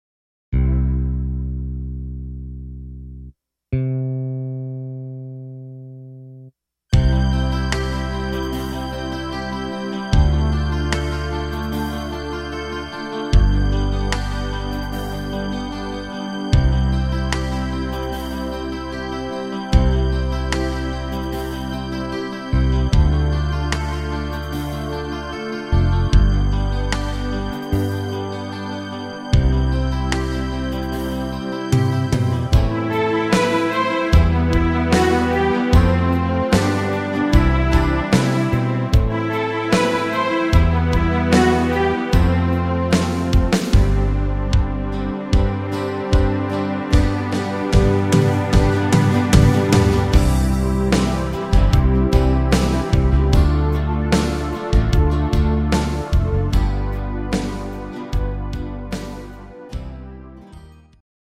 instr. Piano